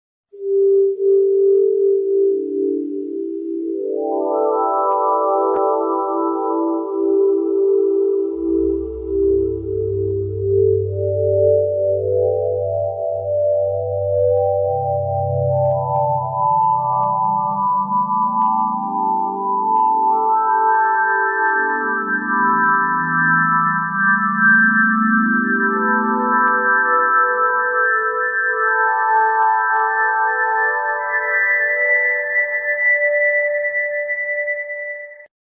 Data Sonifcation: Supernova 1987A (Multiwavelength) Sound Effects Free Download